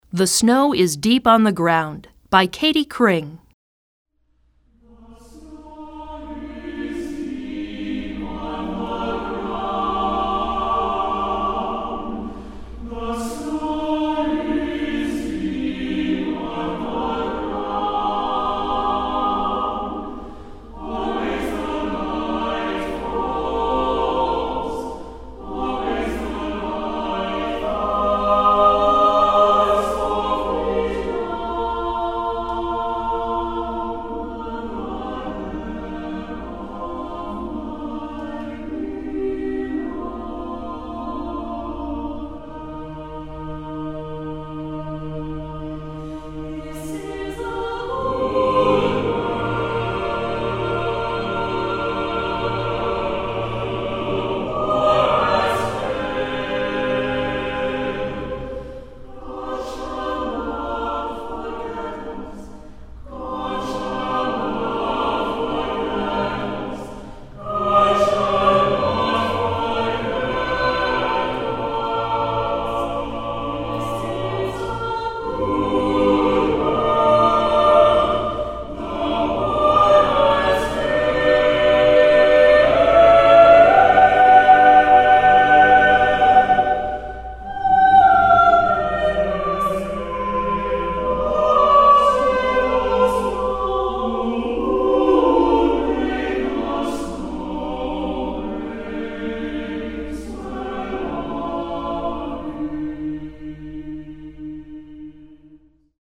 Choeur Mixte SATB a Cappella